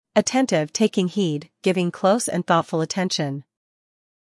英音/ əˈtentɪv / 美音/ əˈtentɪv /